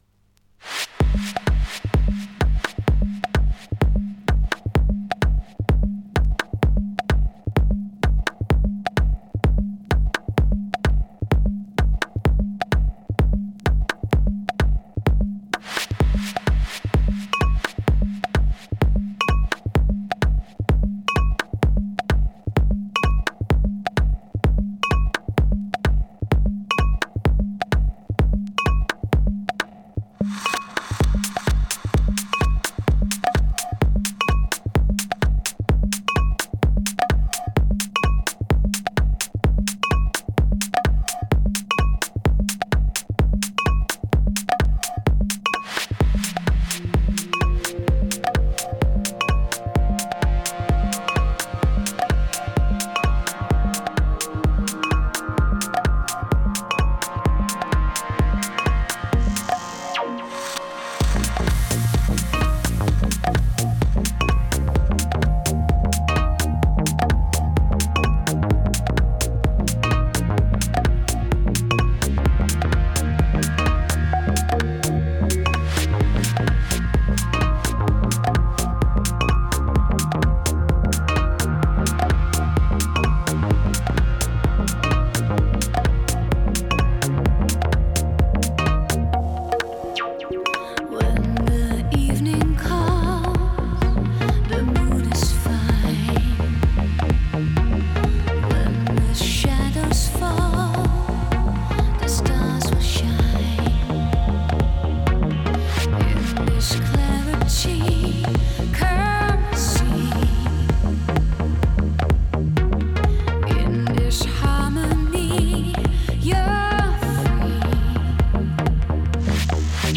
Genre : Trance Progressive.